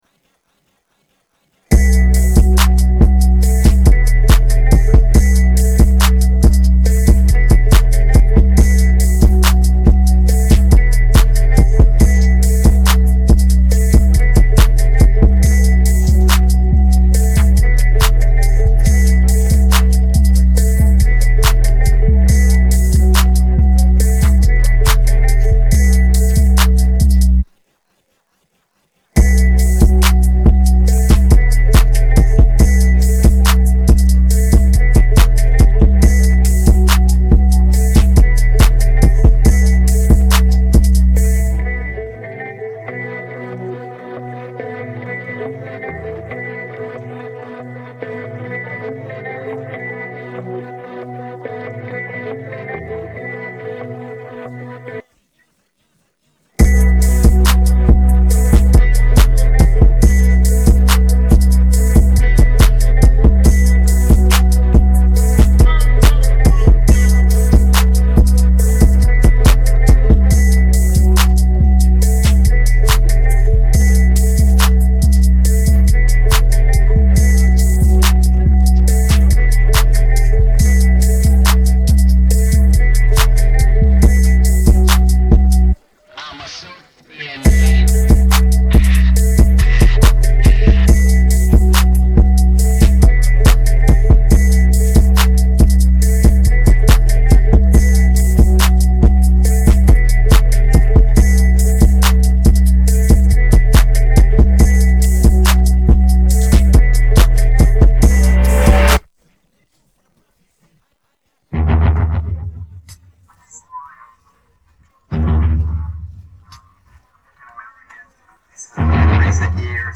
Hintergrundmusik